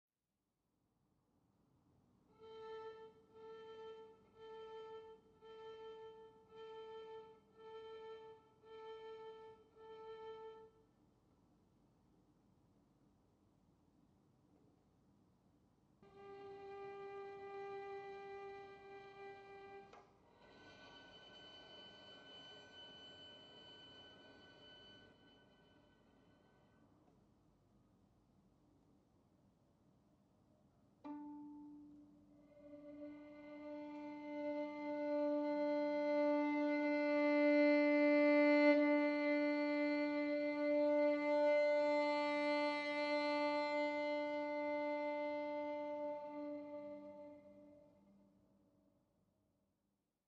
He has just contacted me, with typical restraint: “Have been writing some new pieces for violin lately.”  Was his only comment attached to the first, very short piece.